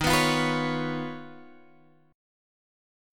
D# 7th Suspended 2nd Sharp 5th